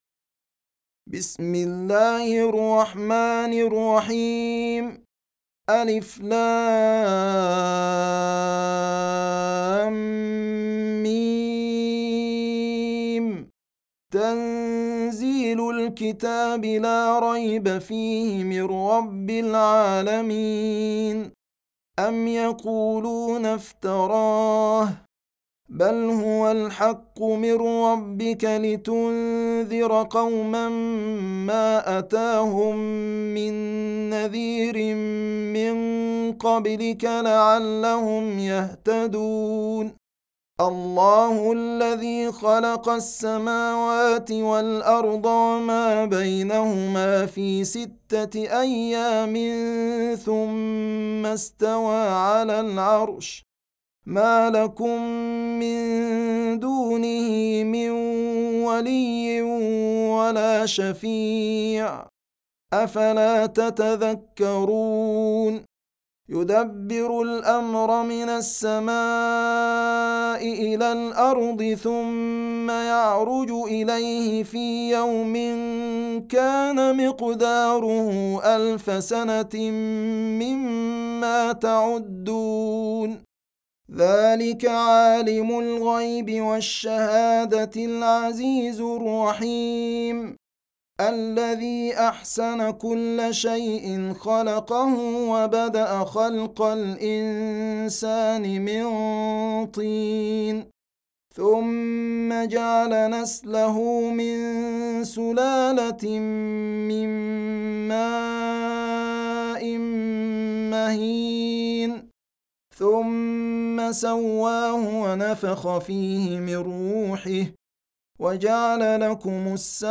Surah Sequence تتابع السورة Download Surah حمّل السورة Reciting Murattalah Audio for 32. Surah As�Sajdah سورة السجدة N.B *Surah Includes Al-Basmalah Reciters Sequents تتابع التلاوات Reciters Repeats تكرار التلاوات